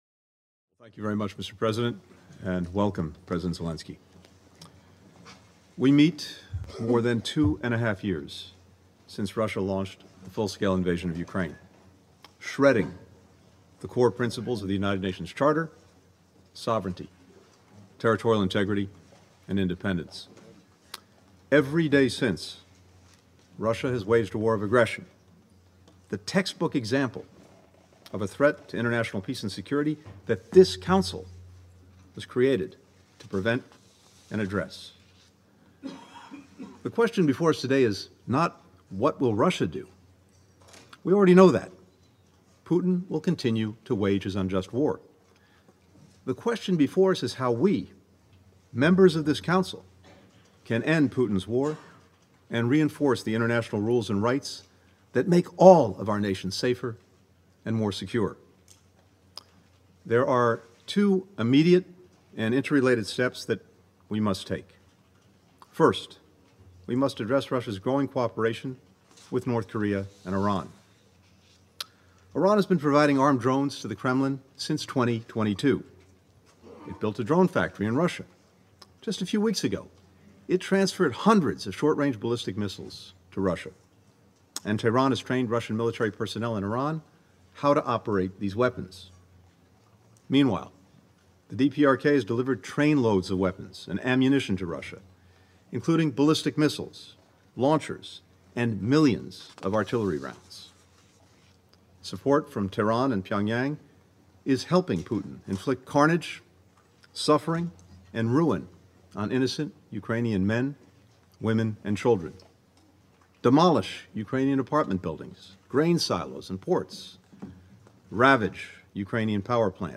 Address to a United Nations Ministerial Meeting on Ukraine
delivered 24 September 2024, United Nations HQ, New York, NY